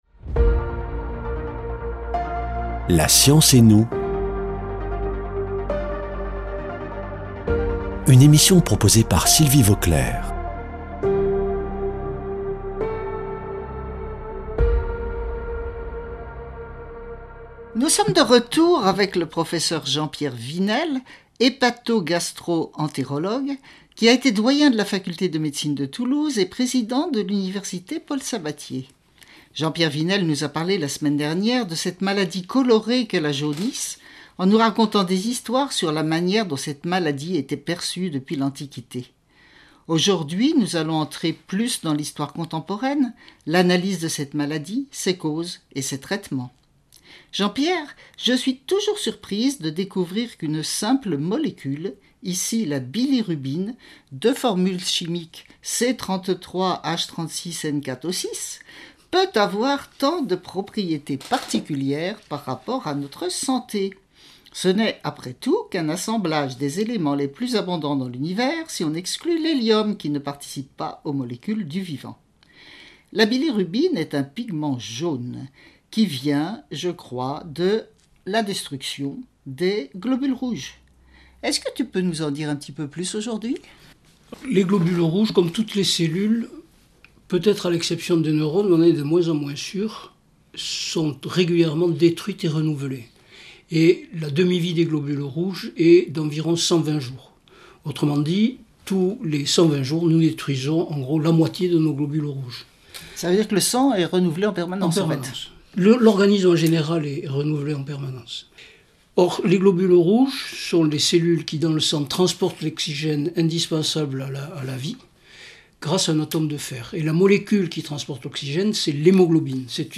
[ Rediffusion ]